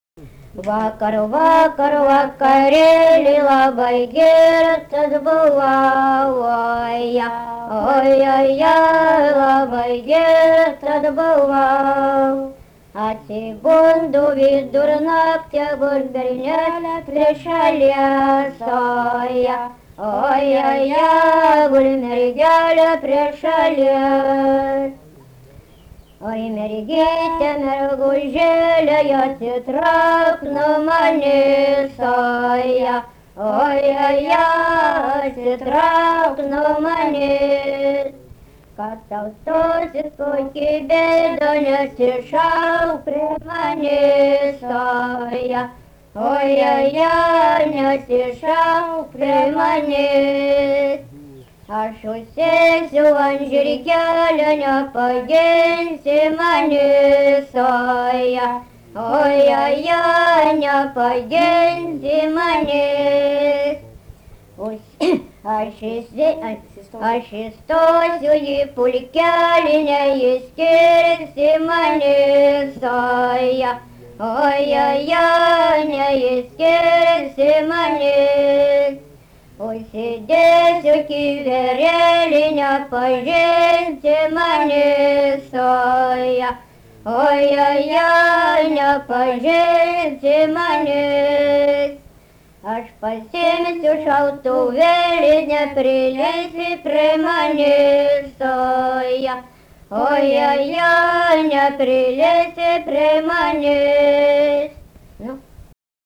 daina, šeimos
Viečiūnai
vokalinis